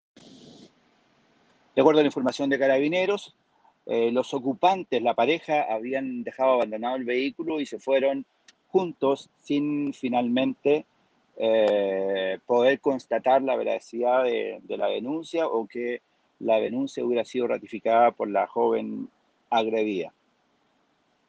Finalmente, el alcalde Eduardo Soto se puso en contacto con Radio Bío Bío para informar que Carabineros dio con el paradero de la pareja, en cuya vivienda familiares informaron que la situación de la pareja “era así”, “un tanto conflictiva”.